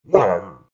COG_VO_grunt.ogg